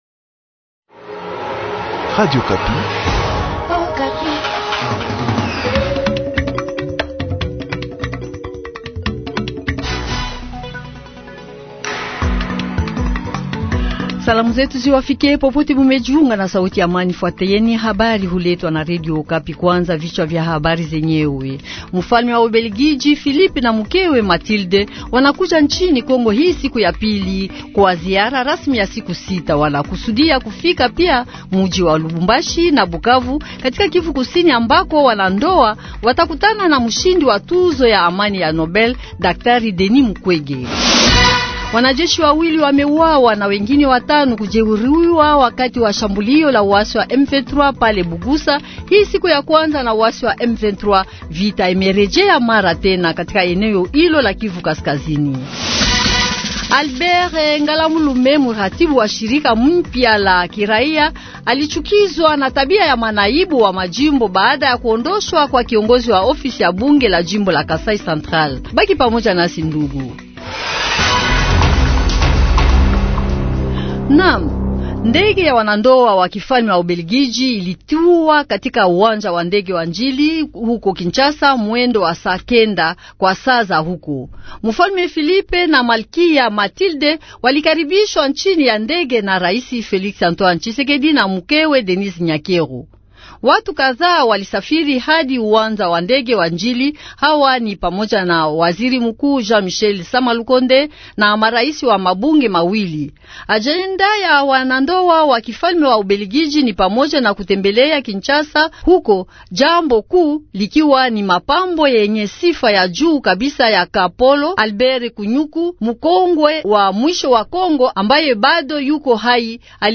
Journal Du Soir